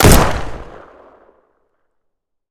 gun_rifle_shot_04.wav